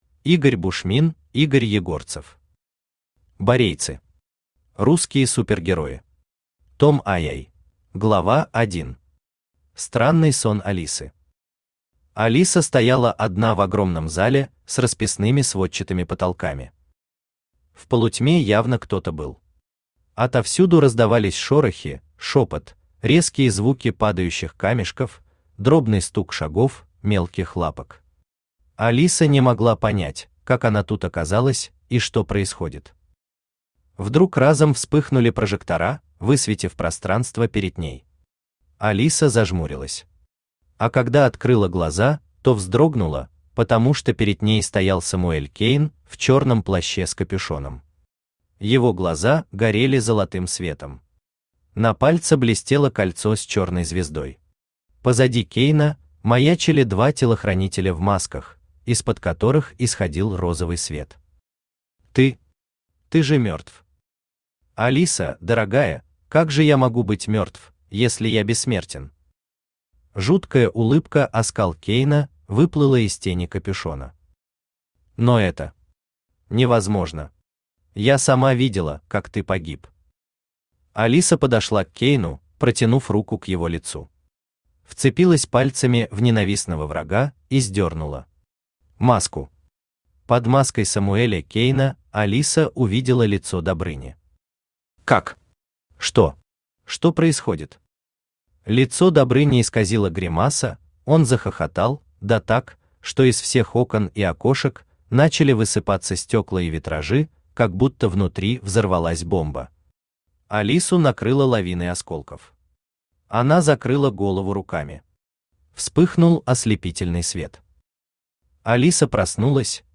Аудиокнига Борейцы. Русские Супергерои. Том II | Библиотека аудиокниг
Aудиокнига Борейцы. Русские Супергерои. Том II Автор Игорь Викторович Егорцев Читает аудиокнигу Авточтец ЛитРес.